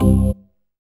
ORGAN-11.wav